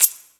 Shaker MadFlavor 3.wav